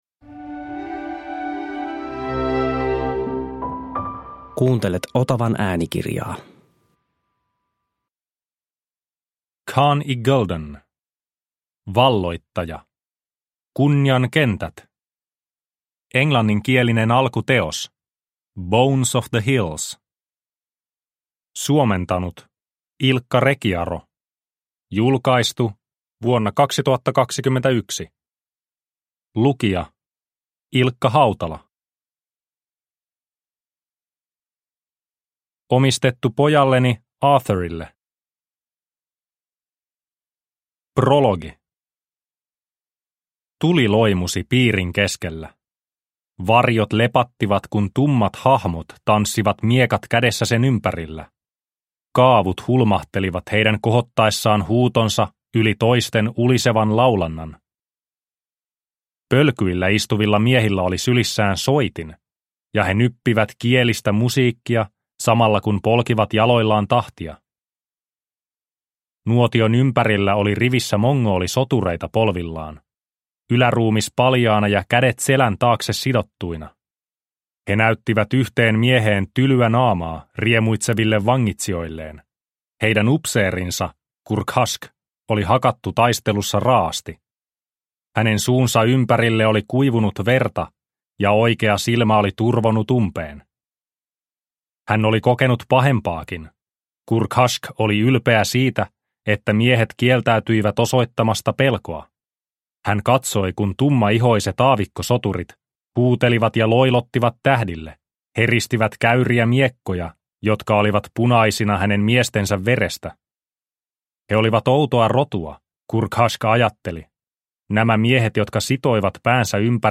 Kunnian kentät – Ljudbok – Laddas ner